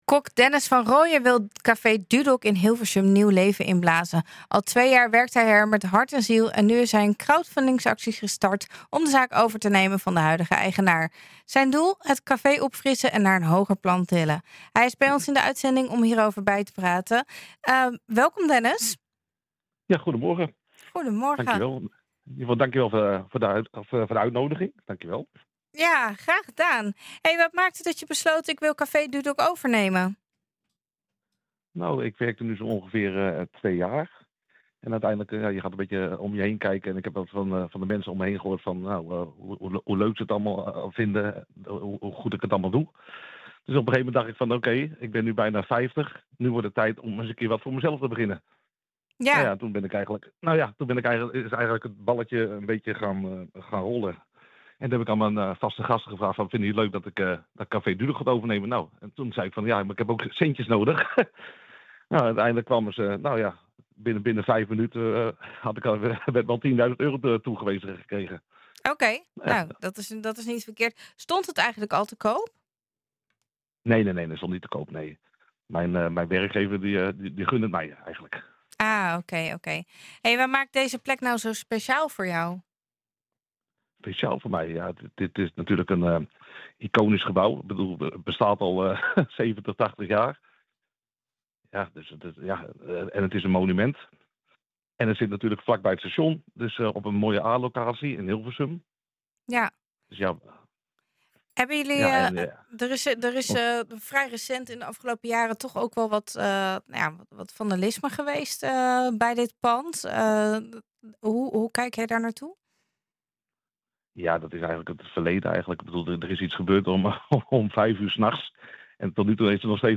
Hij is bij ons in de uitzending om ons hierover bij te praten.